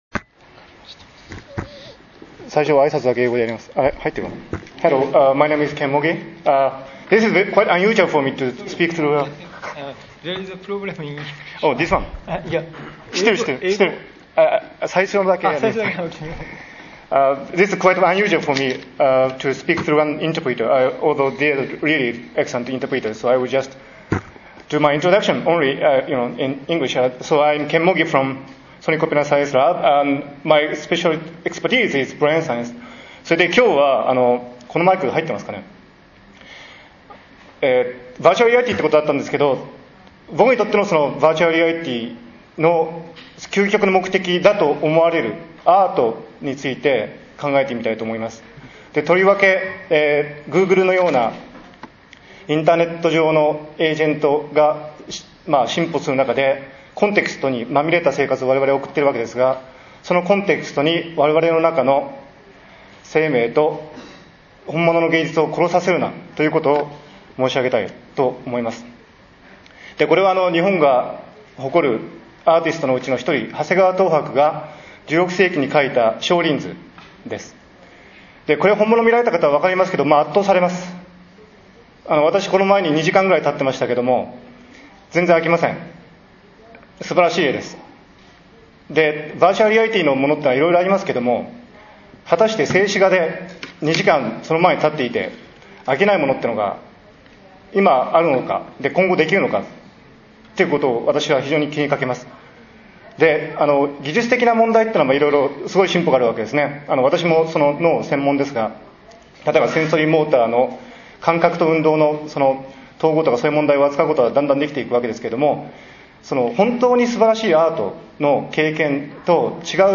日仏学際シンポジウム 講演 Don't let the context ki...
慶應義塾大学 三田キャンパス 東館6Ｆ G-SECラボ